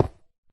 Sound / Minecraft / dig / stone1